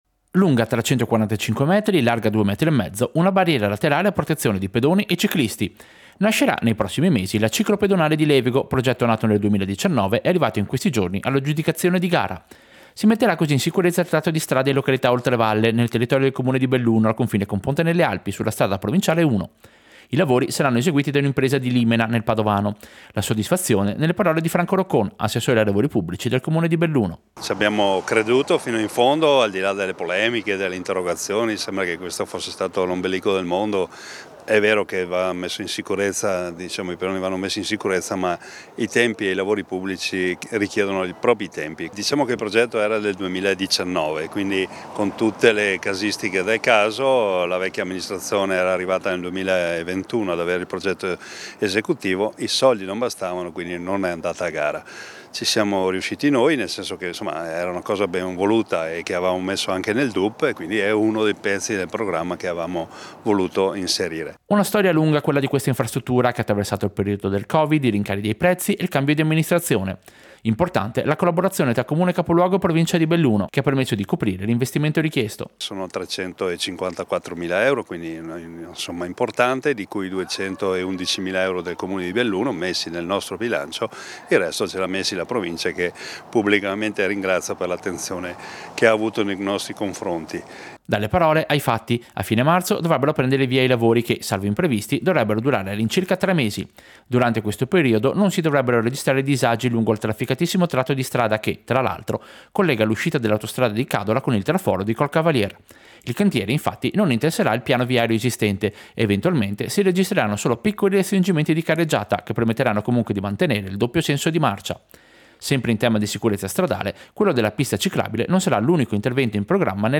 IL SINDACO DI BELLUNO OSCAR DE PELLEGRIN
Servizio-Lavori-ciclopedonale-Levego.mp3